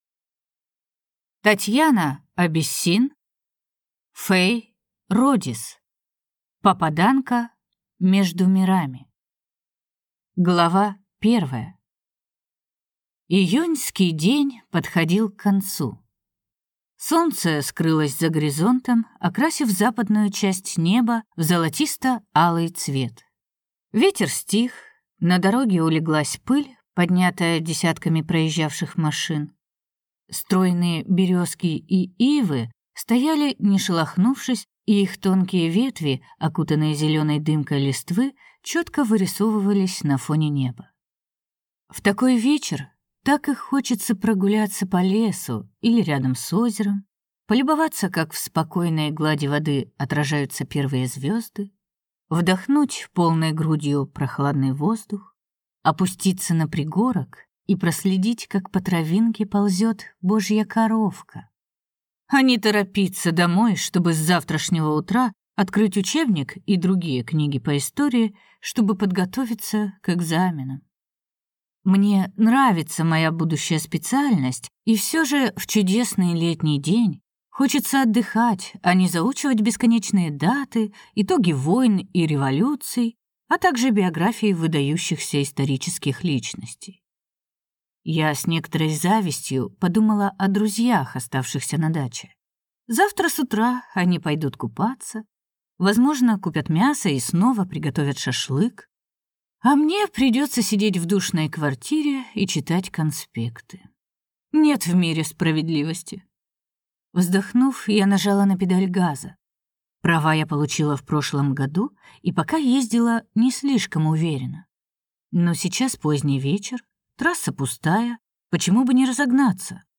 Аудиокнига Попаданка между мирами | Библиотека аудиокниг